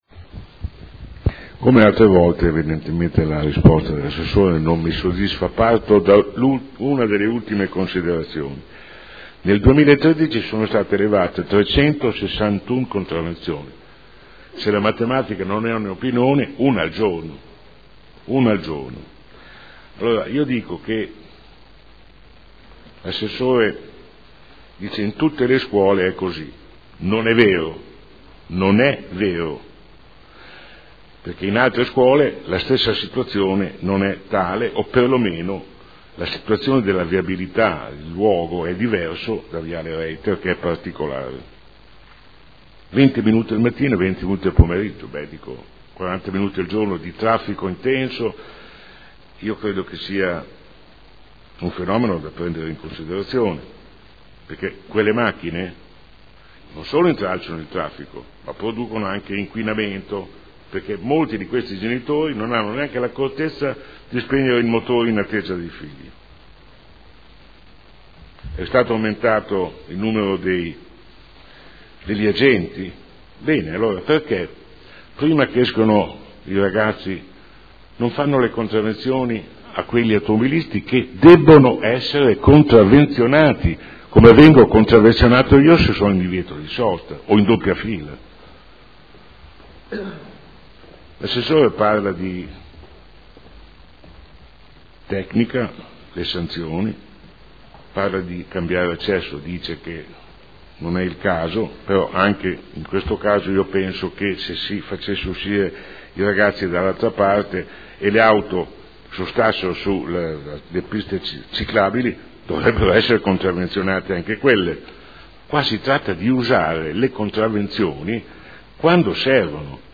Seduta del 7 aprile. Interrogazione del consigliere Bellei (Forza Italia – PdL) avente per oggetto: “Perché la Polizia Municipale non interviene in viale Reiter e in via Misley per evitare o quantomeno disciplinare l’assembramento di auto in doppia fila e in divieto di sosta che attendono al termine delle lezioni gli studenti del Liceo Tassoni e delle Medie Paoli?”.